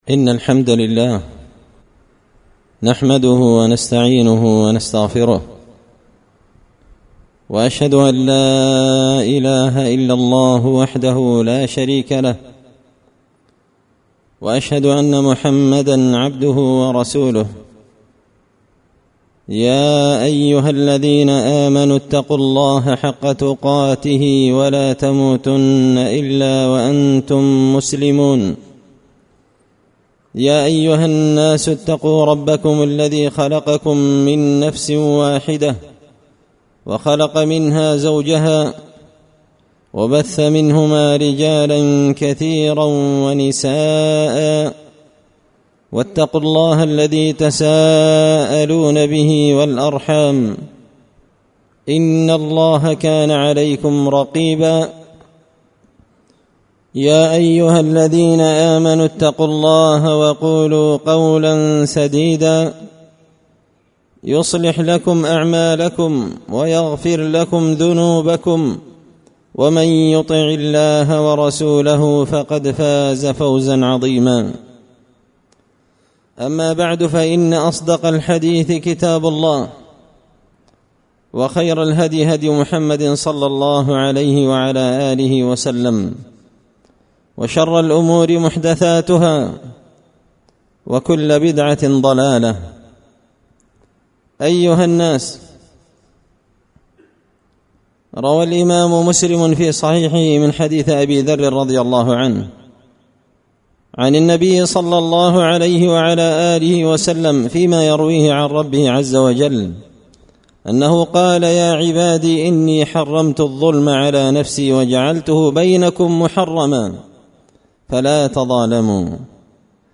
خطبة جمعة بعنوان – التوبة من تأخير التوبة
دار الحديث بمسجد الفرقان ـ قشن ـ المهرة ـ اليمن